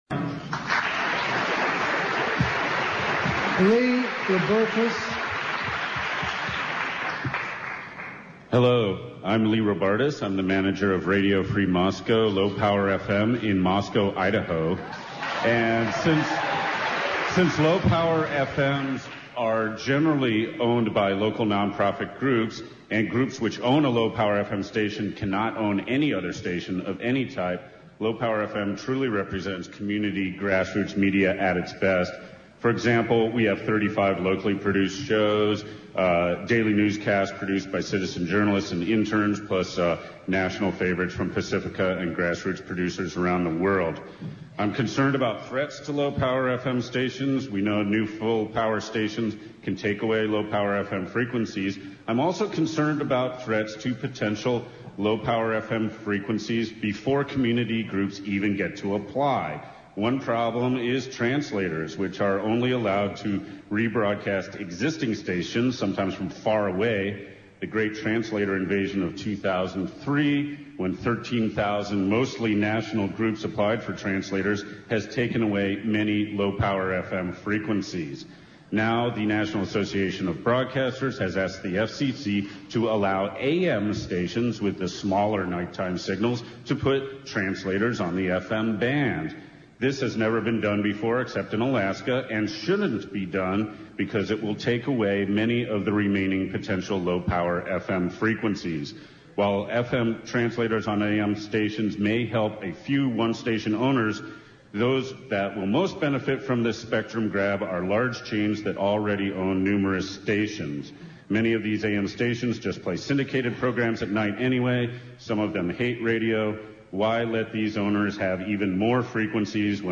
FCC holds public hearings on deregulation : Indybay